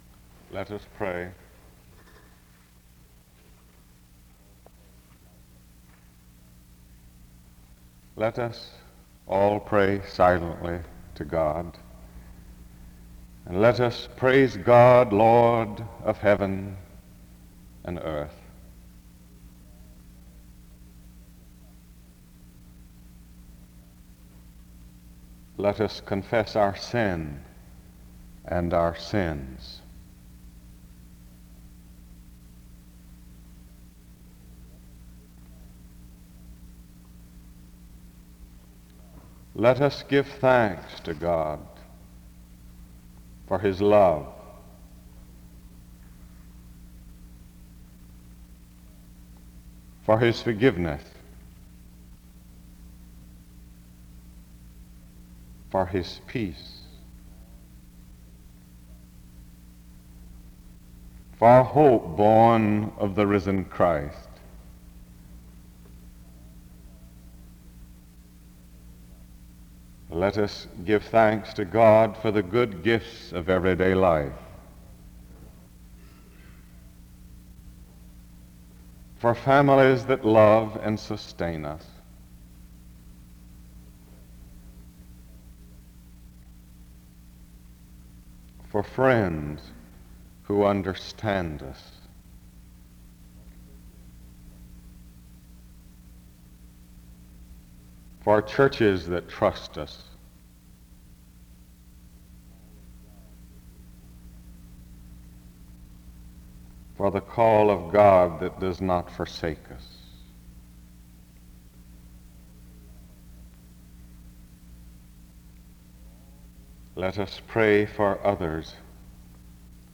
An opening word of prayer is offered from 0:00-3:35. Music plays from 3:36-4:04.
SEBTS Chapel and Special Event Recordings